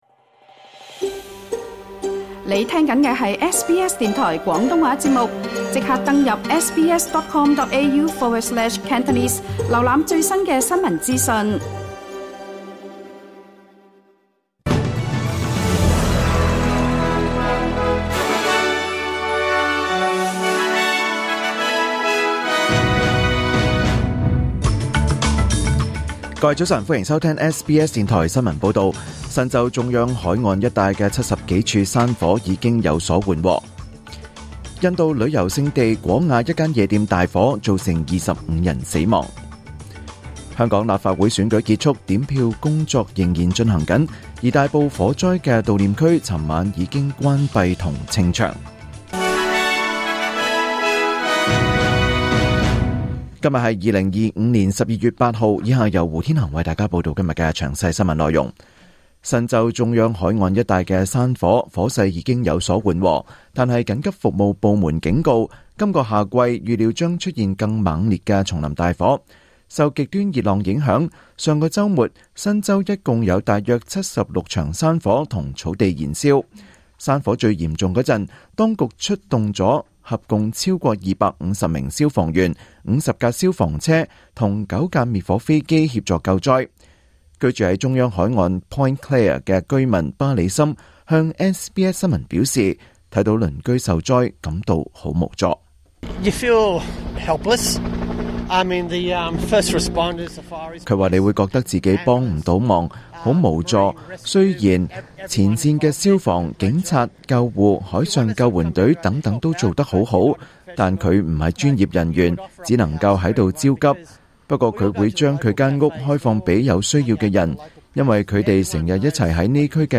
2025年12月8日SBS廣東話節目九點半新聞報道。